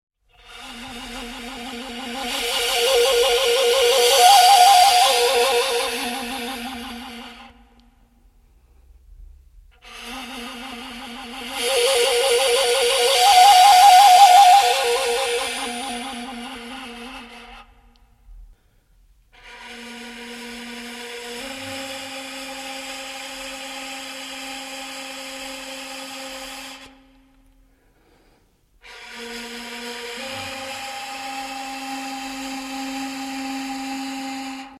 instrumental, Saghai people
voice (khai in kharygha style), yykh (fiddel)
chatkhan (wooden box zither)